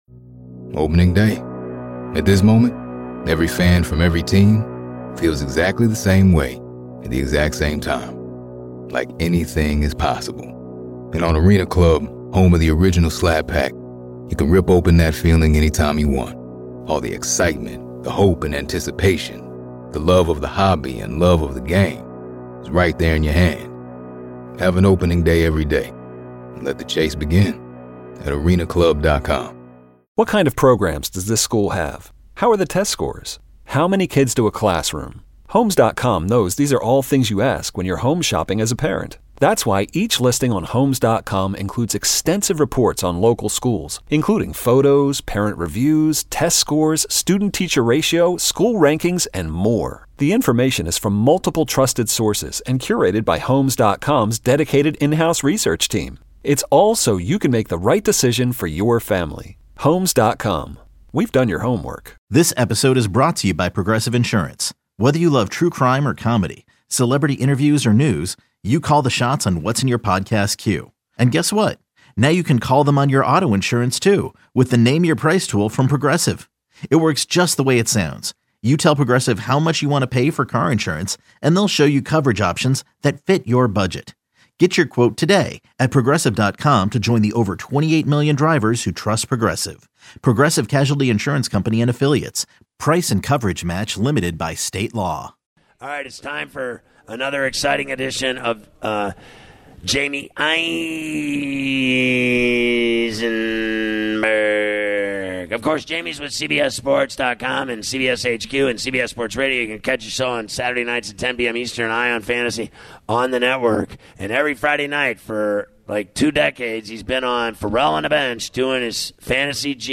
take calls from the fans
Interview